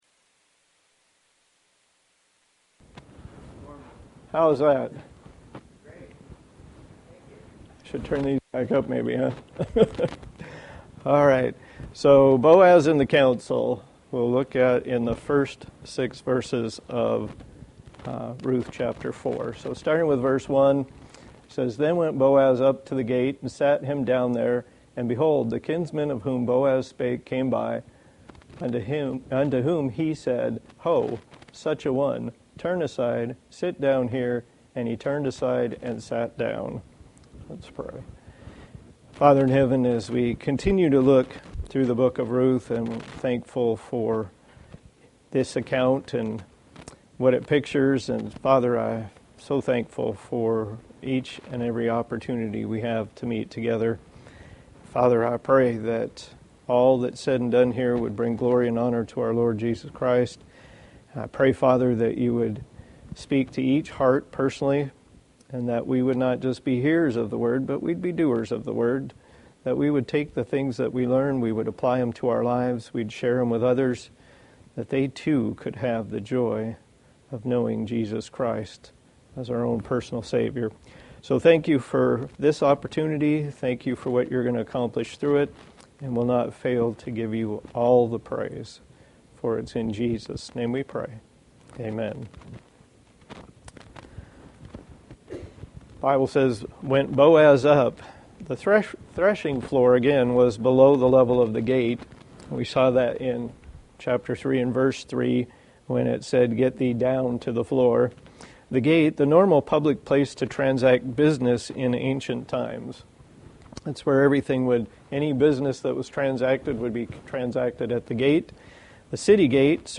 Passage: Ruth 4:1 Service Type: Sunday School